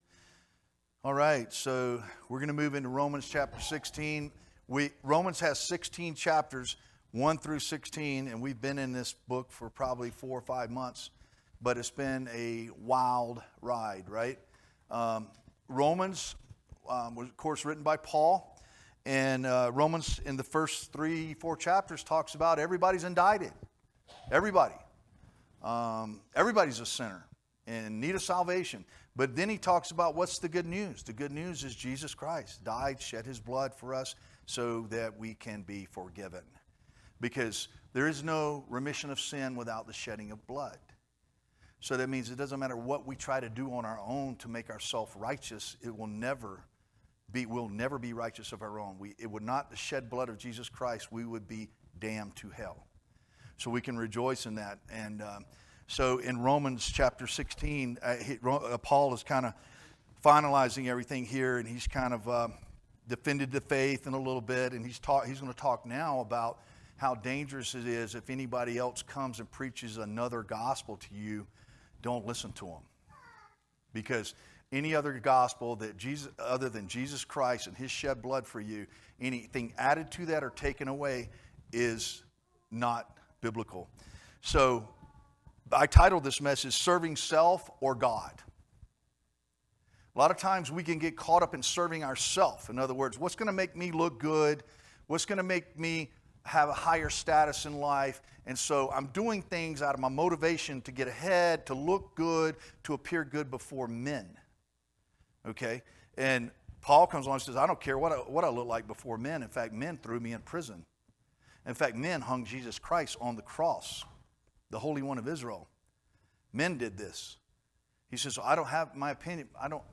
teaches a lesson from the Book or Romans, Chapter 16